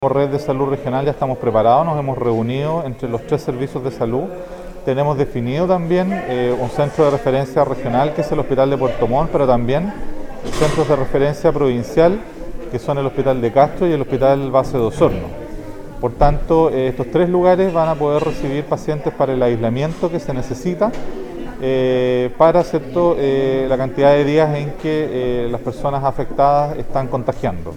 En la zona, el seremi del ramo Carlos Becerra explicó que la medida entrega instrumentos para responder en estos casos, por ejemplo, la autoridad regional podrá establecer el aislamiento de casos o personas bajo sospecha de estar infectadas con la Viruela del Mono y disponer de residencias sanitarias para ello.